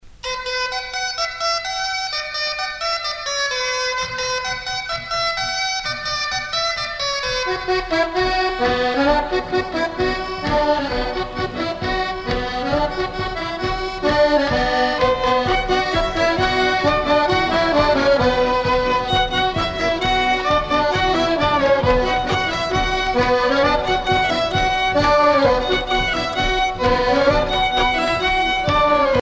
danse : bal à quatre
Pièce musicale éditée